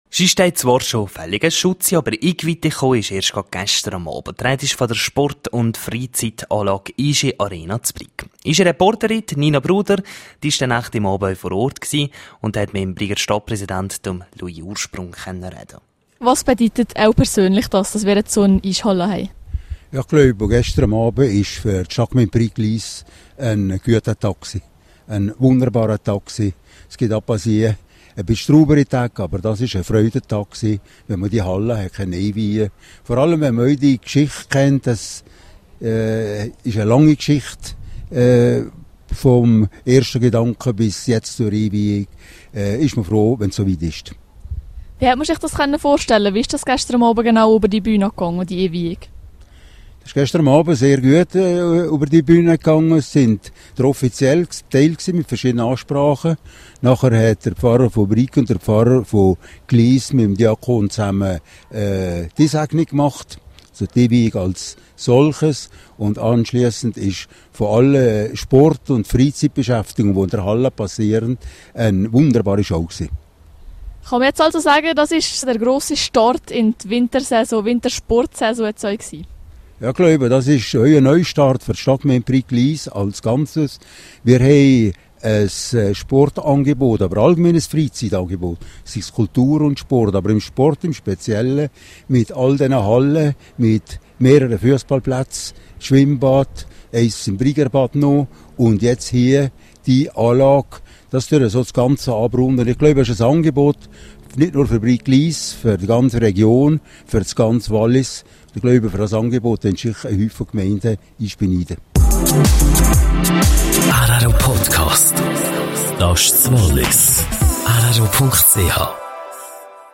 Am Samstag lädt 'Iischi Arena' zum grossen Tag der offenen Tür ein./bn Interview mit Stadtpräsident von Brig-Glis, Louis Ursprung.